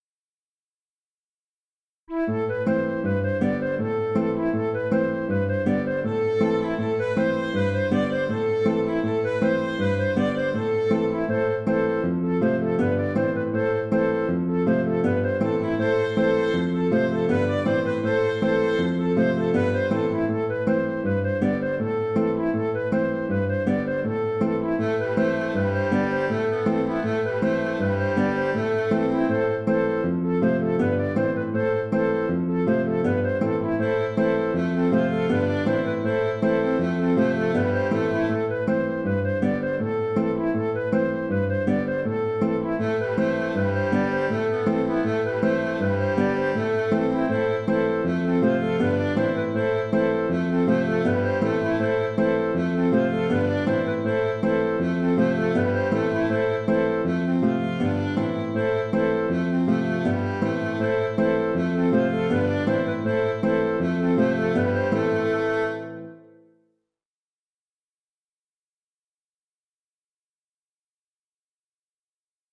Ridée de la lune (Ridée 6 temps) - Musique bretonne
Du point de vue de l’écriture musicale, les rythmes des ridées 6 temps que j’ai pu trouver sur des partitions sont plutôt variés : mesures en 3/4 , ou en 6/4 , parfois même du 4+2 …,J’ai choisi pour ce thème le 6/4 pour bien faire ressortir le rythme binaire de la ridée.